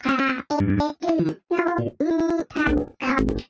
・・・。一部ピッチが乱れてお耳を汚してしまった部分もあるかと思いますが、とりあえず何を歌っているかくらいは伝えられたのではないでしょうか。